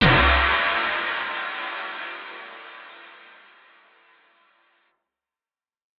Synth Impact 14.wav